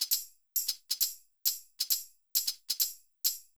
GAR Beat - Mix 5.wav